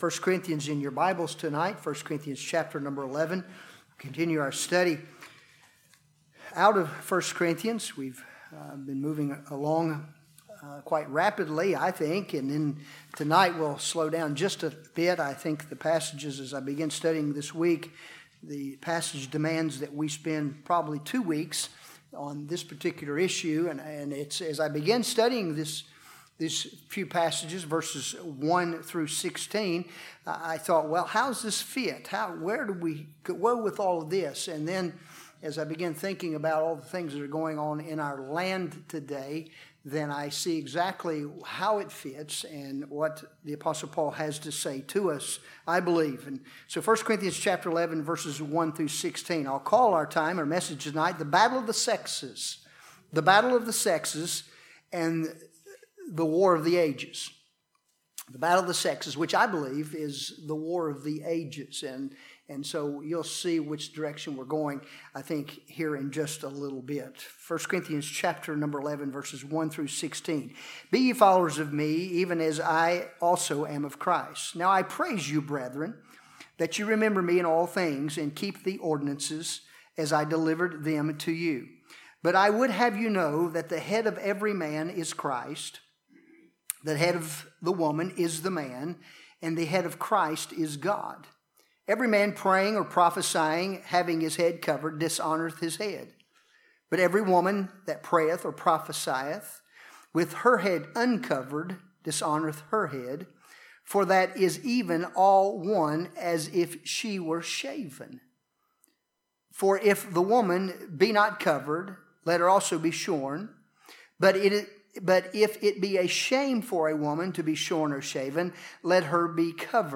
1 Corinthians 11:1-16 Service Type: Wednesday Evening Topics